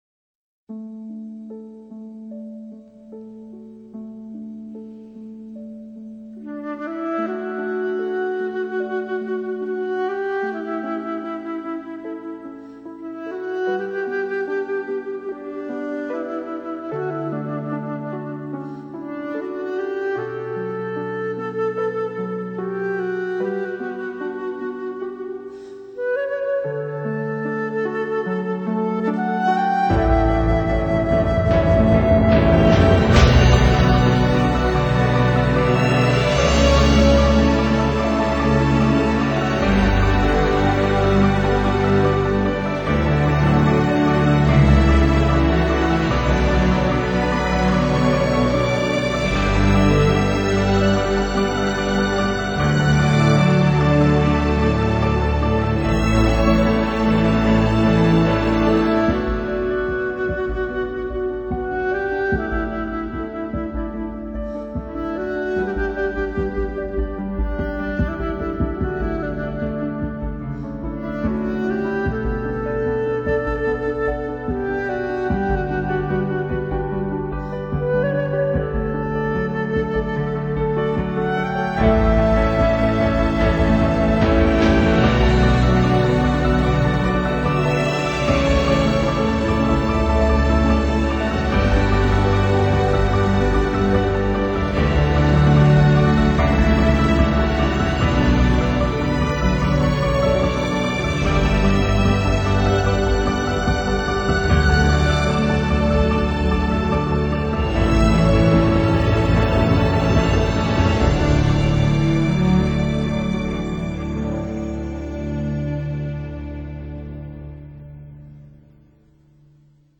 大胆地运用抑扬的旋律，令人向往的轻松口哨声，深情溅落的铙钹声，以及渐渐激烈的内心挣扎作为渲染